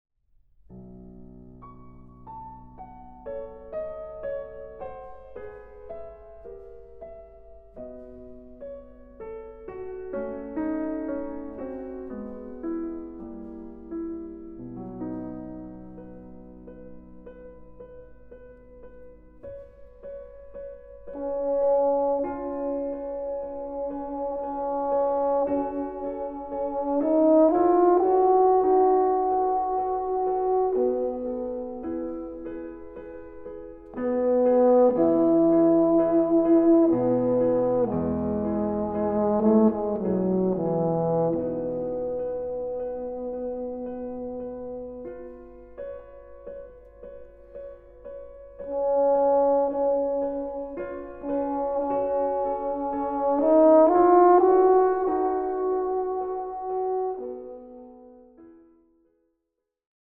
Version for Euphonium and Piano